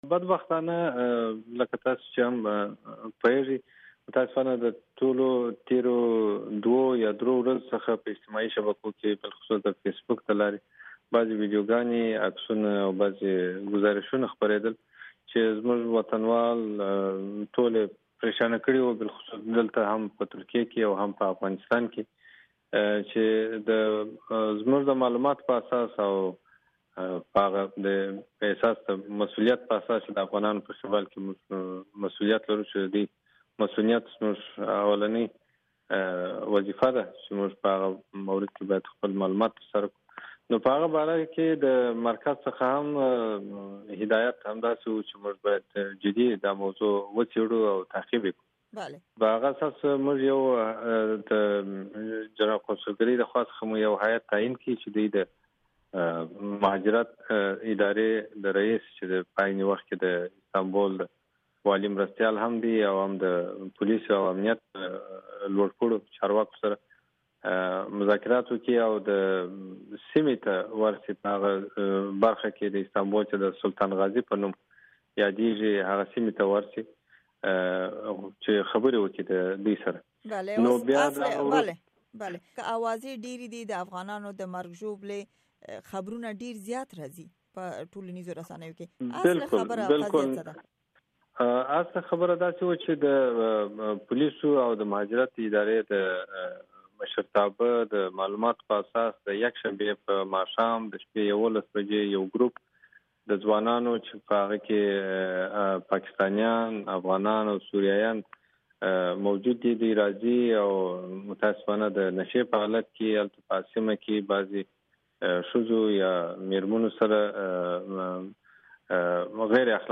په استنبول کې د افغانستان جنرال قونصل عبدالملک قریشي سره مرکه